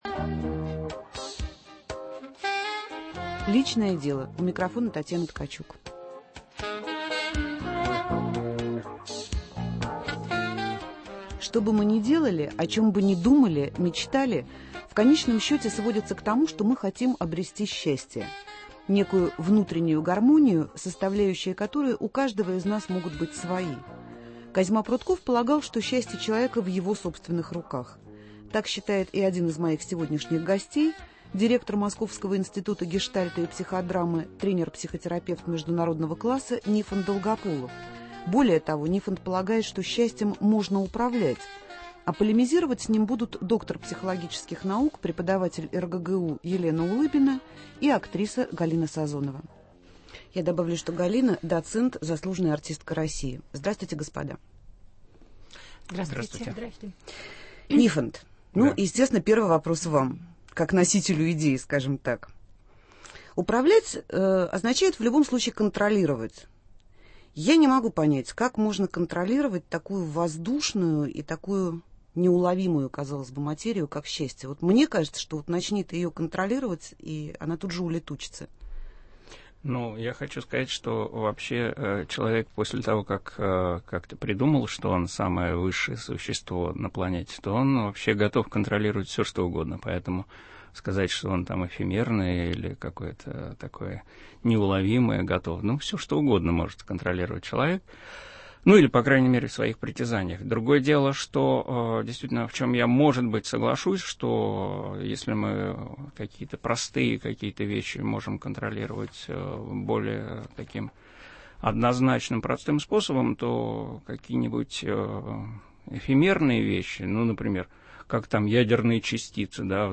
Можно ли, на ваш взгляд, управлять счастьем? В прямом эфире встретятся